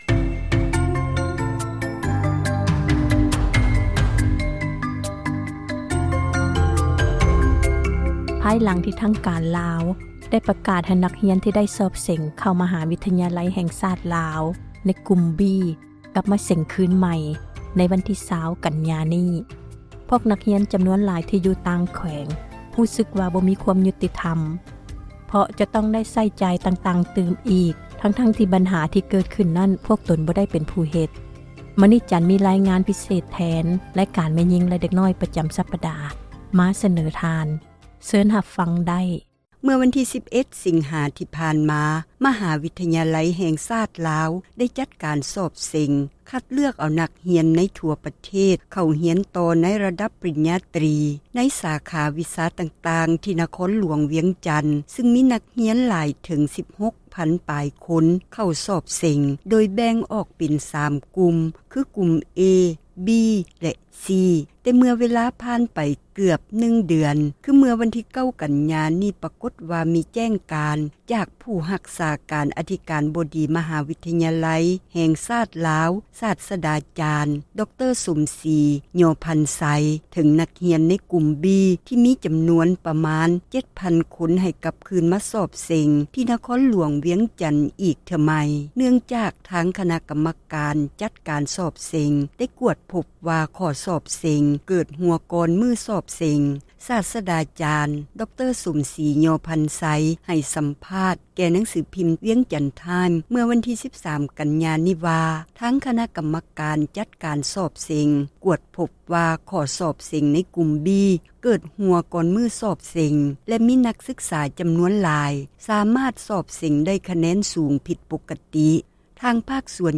ມີຣາຍງານ ພິເສດ ແທນຣາຍການ ແມ່ຍິງ ແລະ ເດັກນ້ອຍ ປະຈໍາສັປດາ.